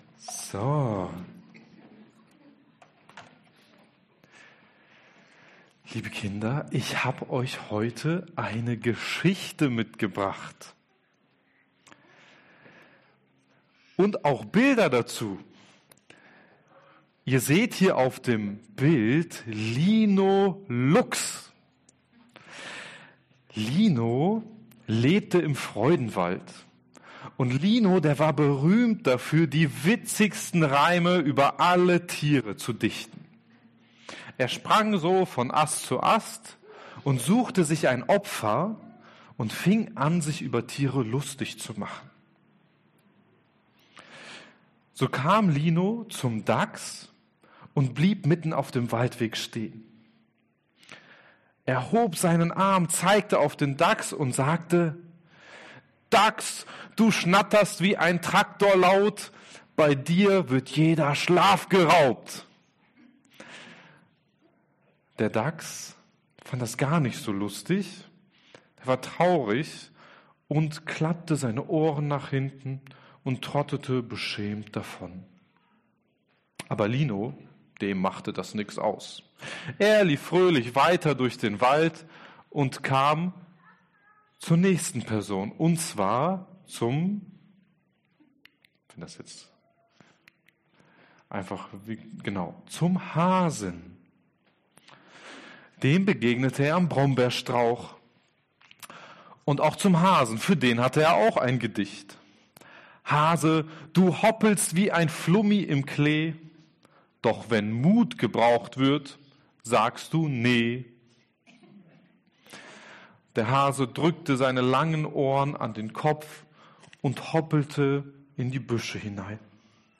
Kinderpredigt: Die Macht der Worte